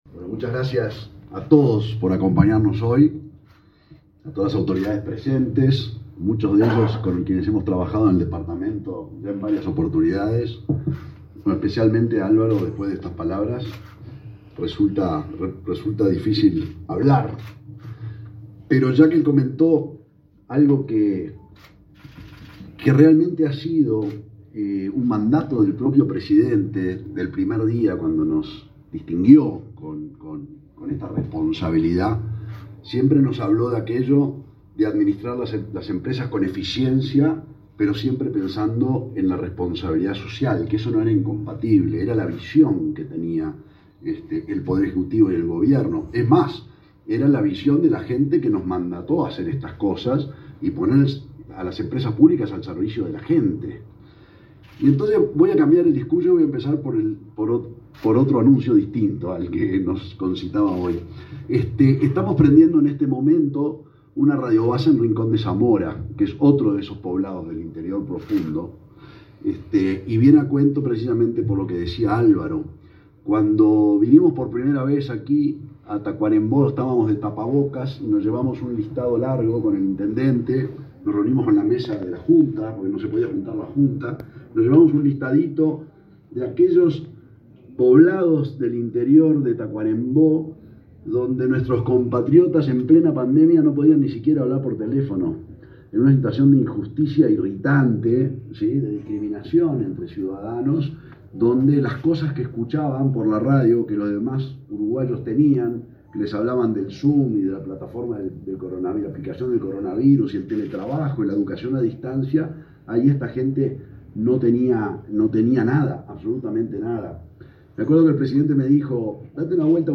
Palabras del presidente de Antel, Gabriel Gurméndez
Palabras del presidente de Antel, Gabriel Gurméndez 10/08/2023 Compartir Facebook X Copiar enlace WhatsApp LinkedIn Antel habilitó la tecnología 5G en la ciudad de Tacuarembó, este 10 de agosto. El presidente de la empresa estatal, Gabriel Gurméndez, disertó en el evento.